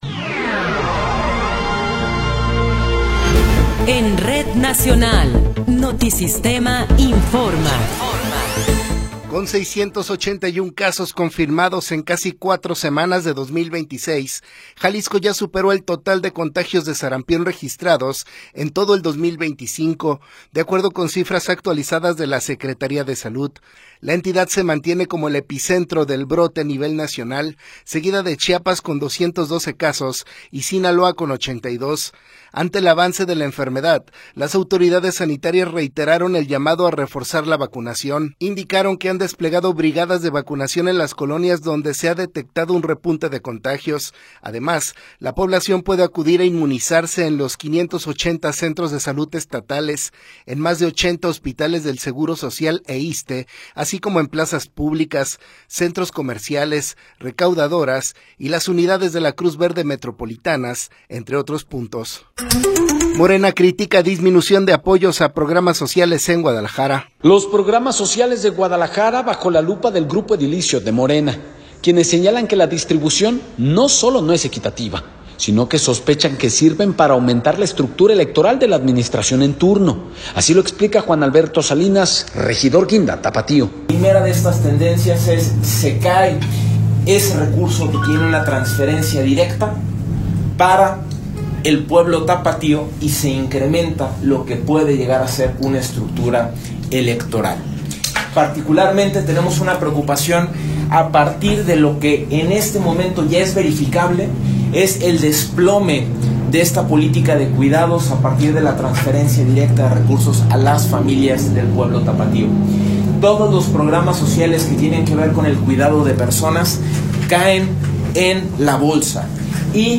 Noticiero 17 hrs. – 28 de Enero de 2026